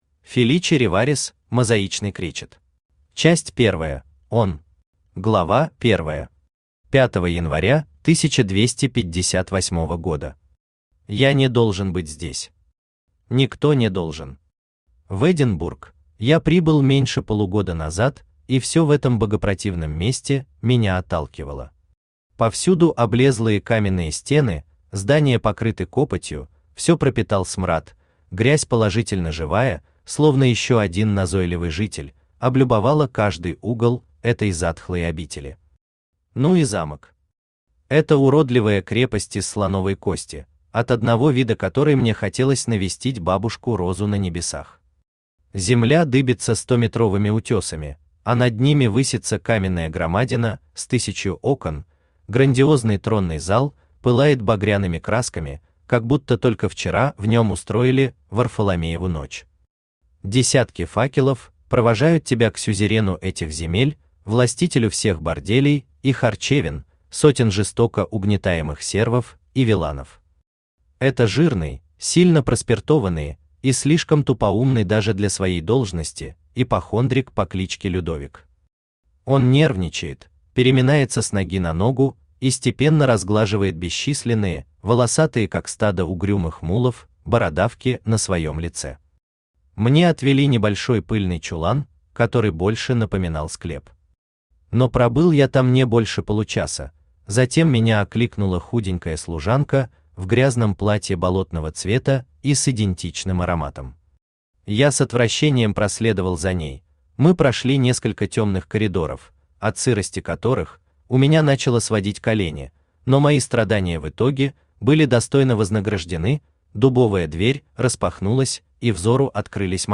Аудиокнига Мозаичный кречет | Библиотека аудиокниг